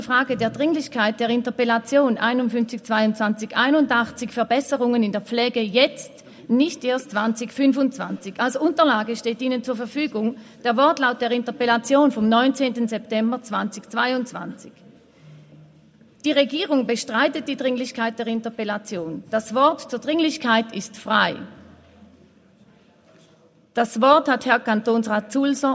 Session des Kantonsrates vom 19. bis 21. September 2022